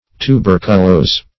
Search Result for " tuberculose" : The Collaborative International Dictionary of English v.0.48: Tuberculose \Tu*ber"cu*lose`\, Tuberculous \Tu*ber"cu*lous\, a. Having tubercles; affected with, or characterized by, tubercles; tubercular.